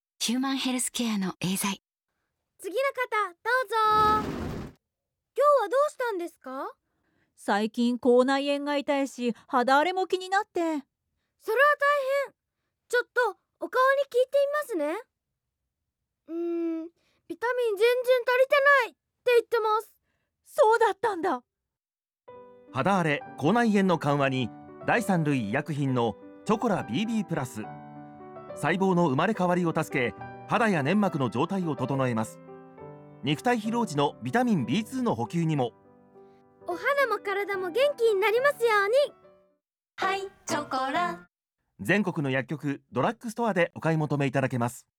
TOP 音声CM・楽曲CMクリエイティブ事例 音声CM 新セルベール整胃プレミアム＜錠＞‧＜細粒＞／チョコラBBプラス（エーザイ株式会社様）
子どもの声で聴く人の関心を引き付けるつくりになっています。
CMでは、肌荒れなど女性の悩みをテーマに「チョコラBBプラス」を紹介。ユーモラスなやりとりの中で製品の特長を自然に届け、親しみやすさや製品への信頼感が生まれる演出にしています。